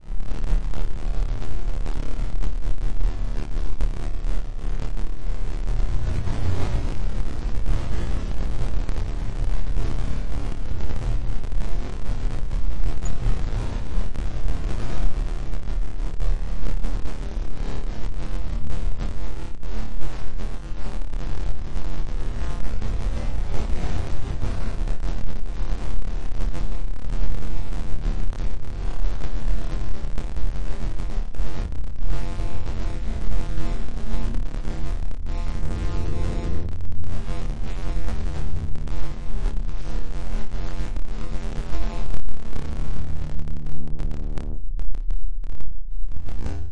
Tag: 电气 电子 出问题 sounddesign 平移 数字 声音效果 静态 无人驾驶飞机 脉冲 黑暗 扭曲 未来 处理 抽象的 sounddesign SFX 科幻 噪声